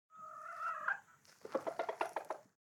chicken_cluck.ogg